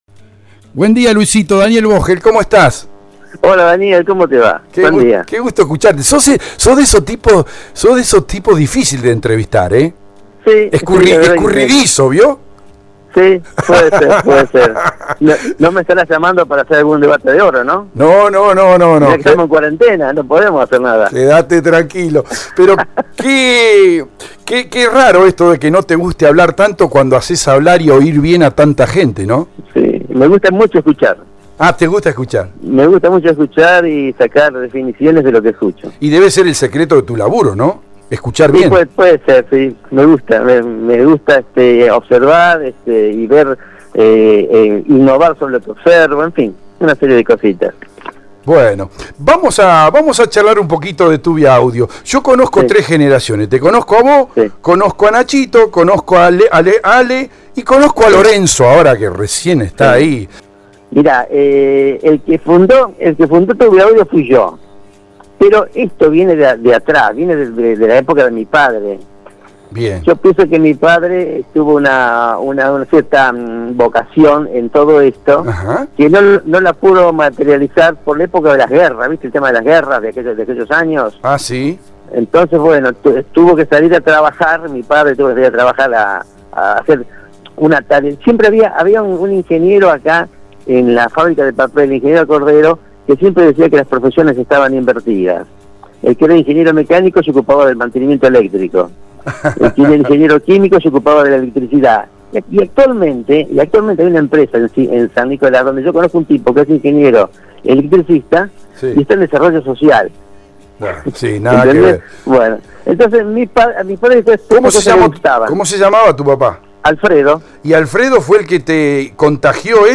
en su programa “Con Zeta” de radio EL DEBATE, entrevistó